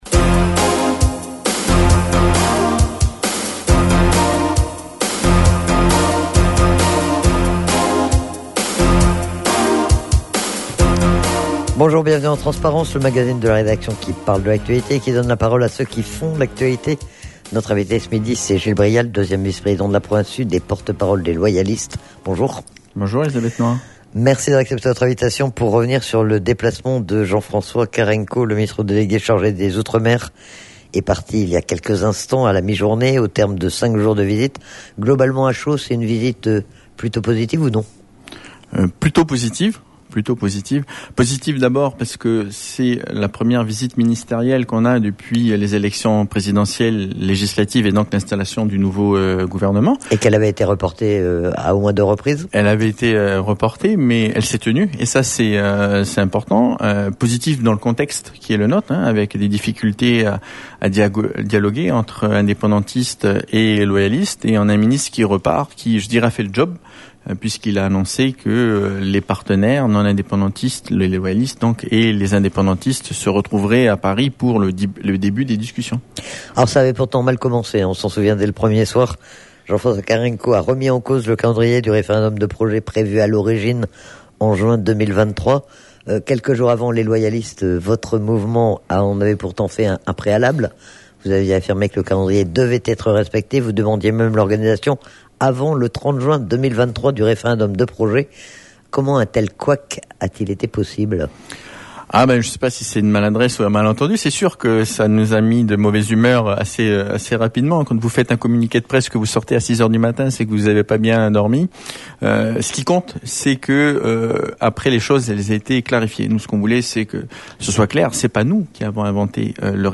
Menu La fréquence aux couleurs de la France En direct Accueil Podcasts TRANSPARENCE : 16/09/22 TRANSPARENCE : 16/09/22 15 septembre 2022 à 15:04 Écouter Télécharger C'est Gil Brial, 2ème vice-président de la Province Sud et porte-parole des Loyalistes qui était l'invité du magazine Transparence. Il est notamment revenu sur la visite du ministre chargé des outremers, Jean-François Carenco.